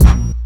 Kick 21.wav